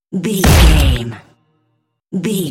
Dramatic drum hit electricity debris
Sound Effects
Atonal
heavy
intense
dark
aggressive
hits